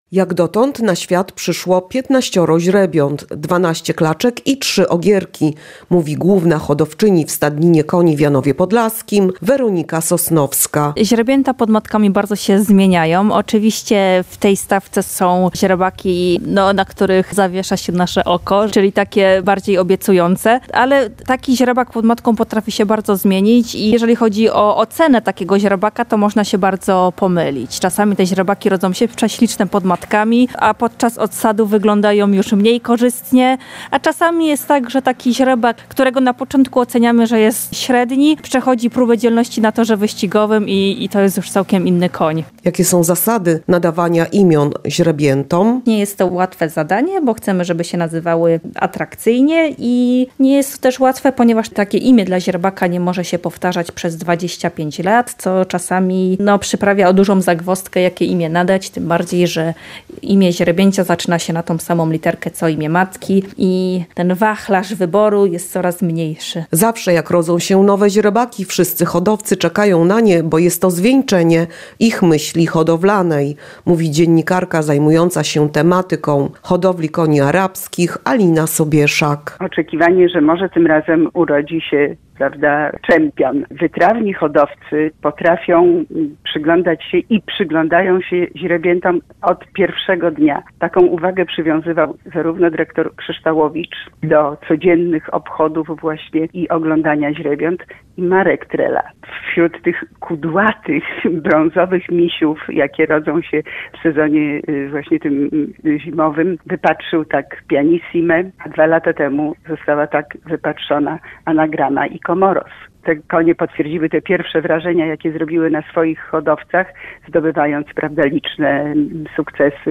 mówi w rozmowie z Radiem Lublin